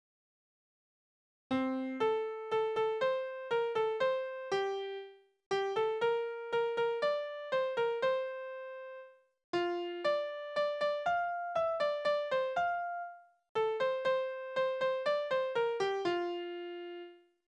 Trinklieder: Es kann ja nicht immer so bleiben
Tonart: F-Dur
Taktart: 4/4
Tonumfang: Oktave, Quarte